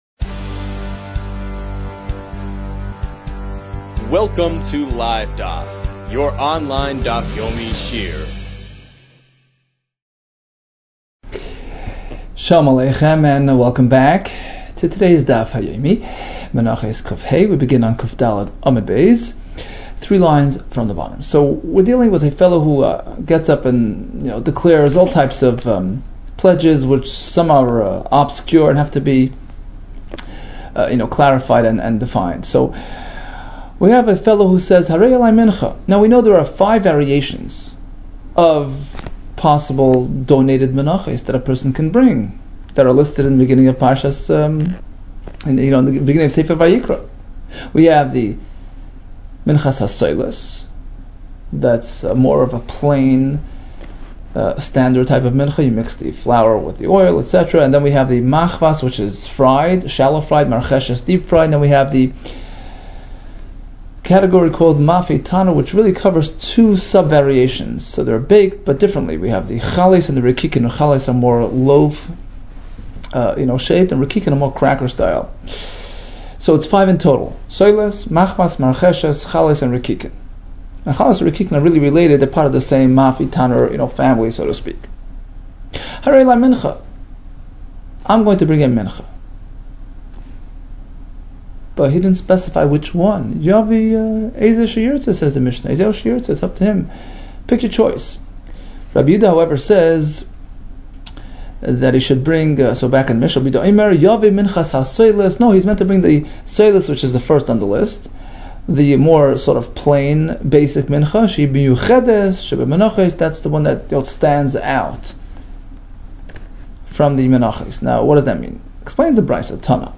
Menachos 105 - מנחות קה | Daf Yomi Online Shiur | Livedaf